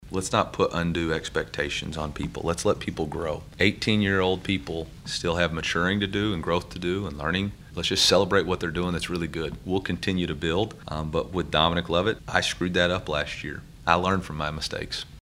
Mizzou football coach Eli Drinkwitz spoke with the media on Tuesday ahead of the Tigers matchup with number one Georgia.